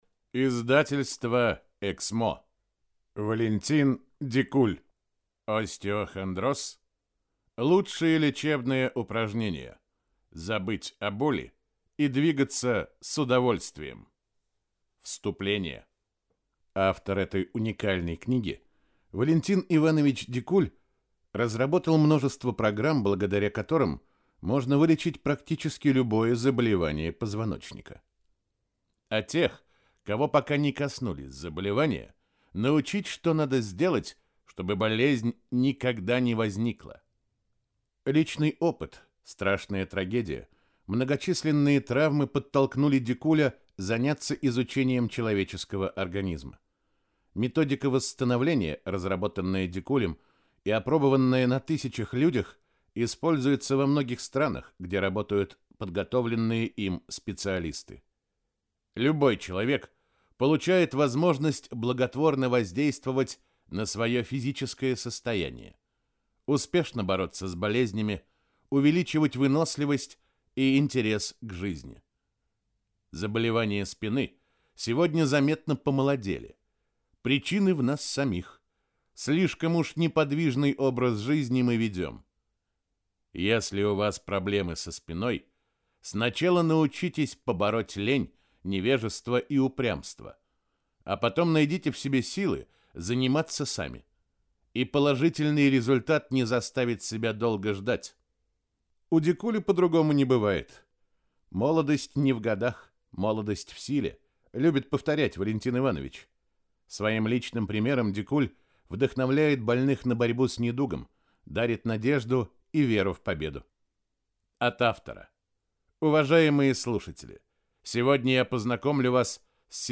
Аудиокнига Остеохондроз? Лучшие лечебные упражнения | Библиотека аудиокниг